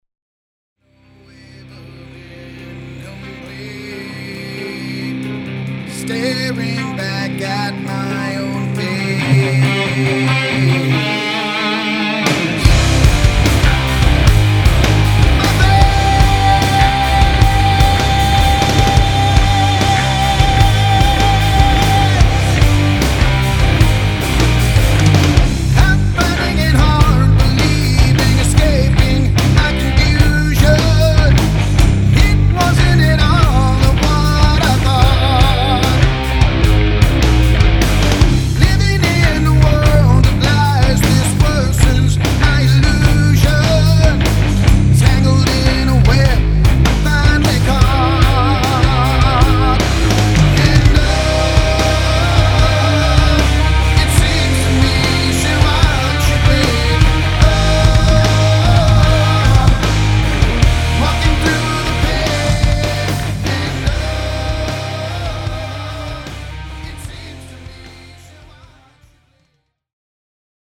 ROCK_MIX-MASTER.mp3